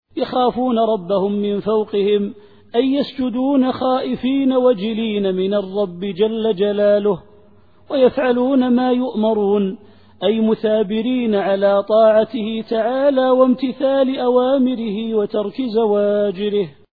التفسير الصوتي [النحل / 50]